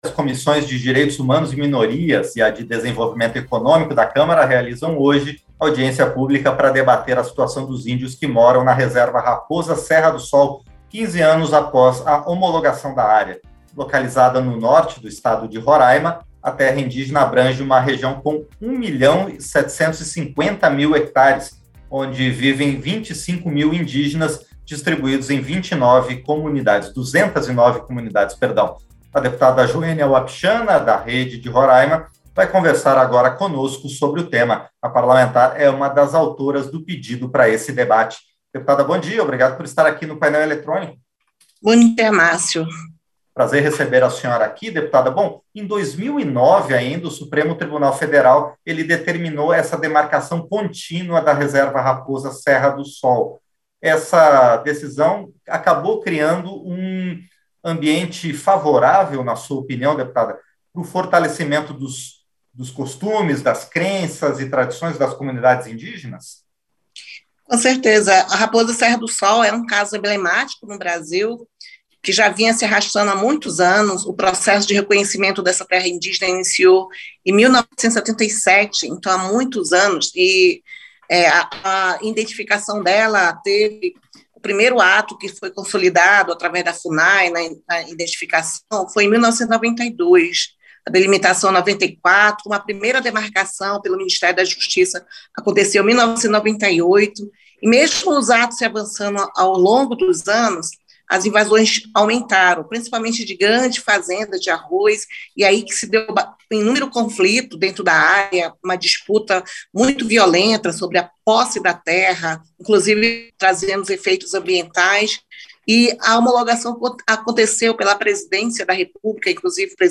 Entrevista - dep. Joenia Wapichana (Rede-RR)